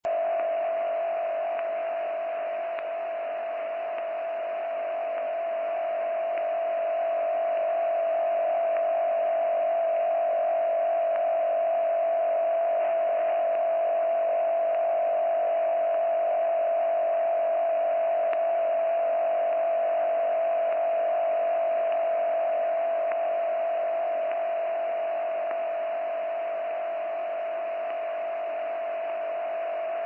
Monitored sound (MP3 30sec., 117KB)